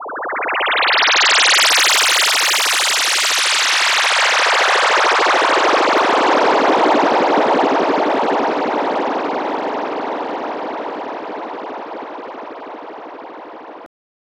Session 04 - SFX 02.wav